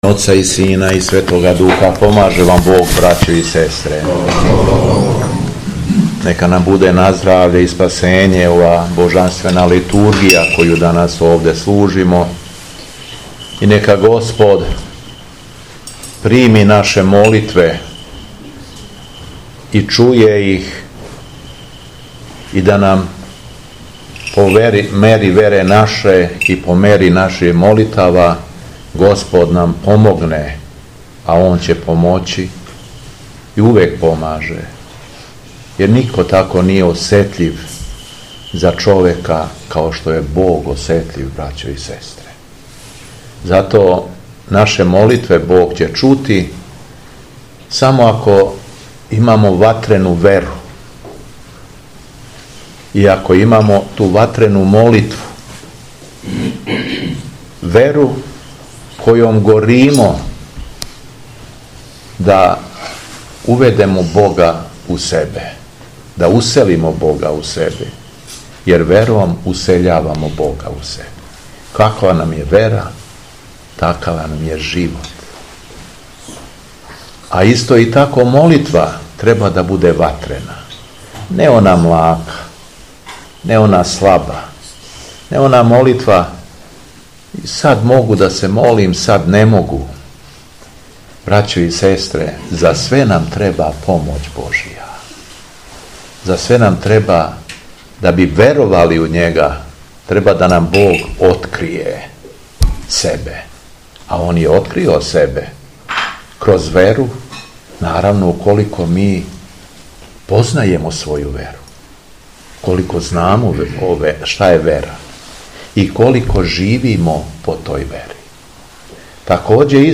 У суботу 5. недеље Васкршњег поста (субота акатиста), 5. априла 2025. године, Његово Високопреосвештенство Митрополит шумадијски Г. Јован служио је Свету Архијерејску Литургију у храму Свете Петке у селу Ботуње надомак Крагујевца.
Беседа Његовог Високопреосвештенства Митрополита шумадијског г. Јована